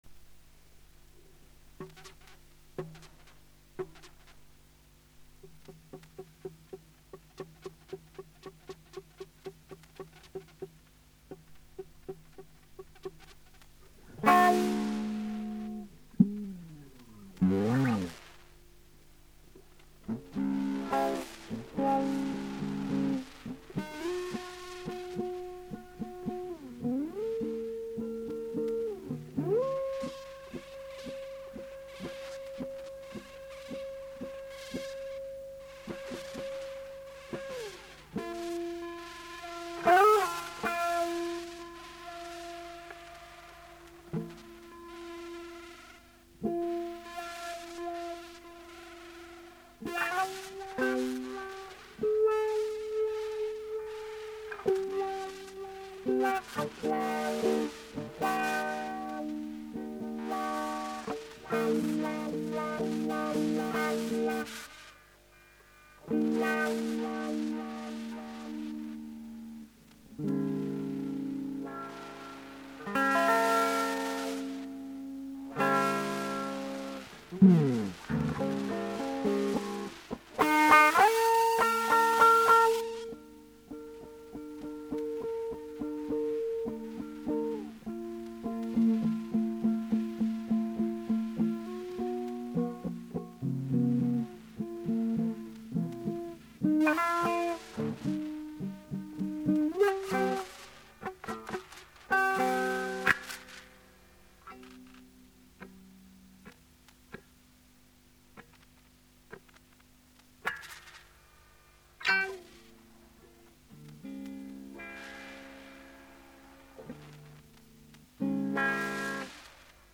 ヨレてんが快適ゃな終了